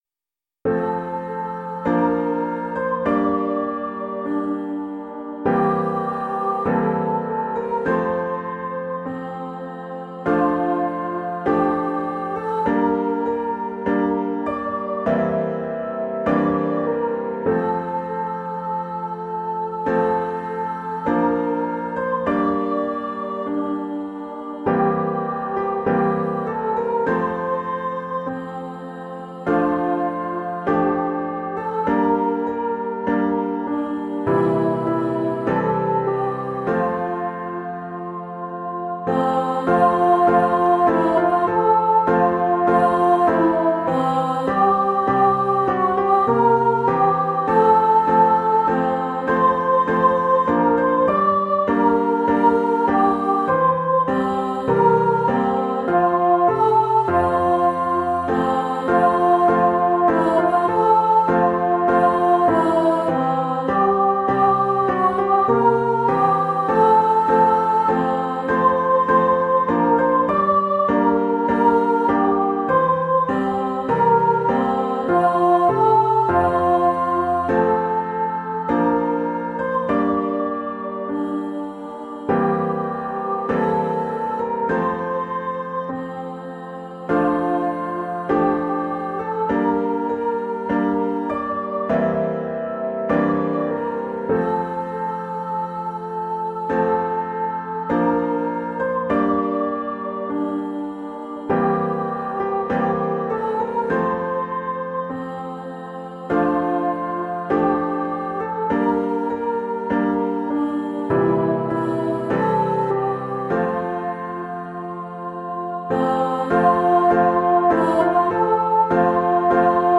Each Song has a synthesised recording for listening.